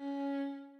Paradise/sound/violin/Db4.ogg at 355666e1a825252a4d08fa4e5cfced85e107ce39